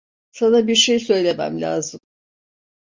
Phát âm là (IPA)
/laːzɯm/